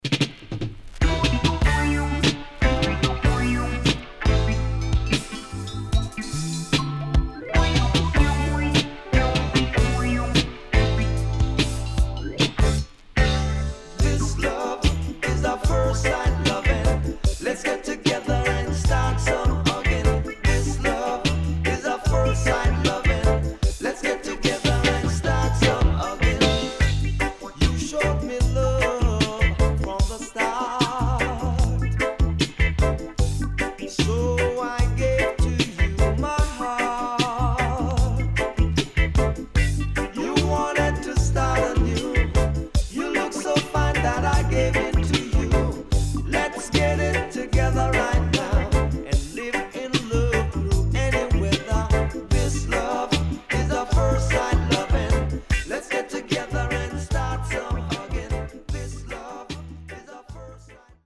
HOME > LP [DANCEHALL]  >  EARLY 80’s
SIDE A:少しノイズ入りますが良好です。